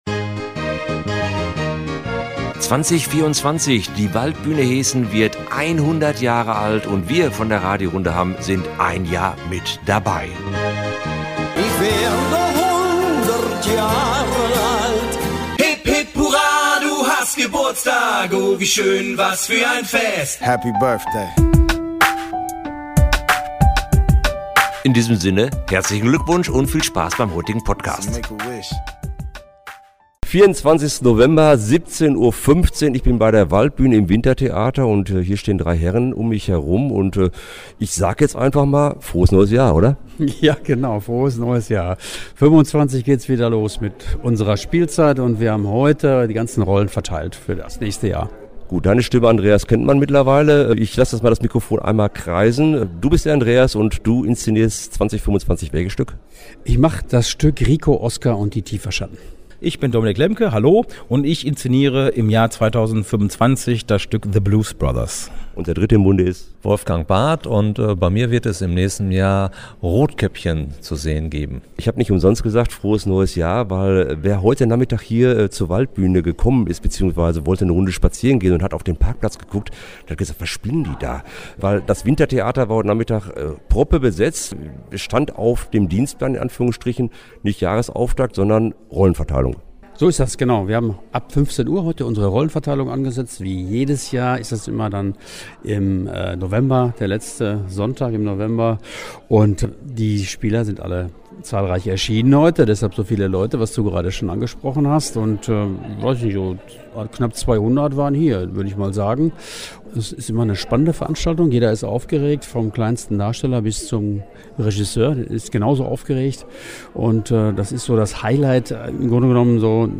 Im Rahmen unseres Jubel-Podcasts stand das Spielleitertrio 2025 am Ende der Veranstaltung am 24. November für uns und Euch Rede und Antwort.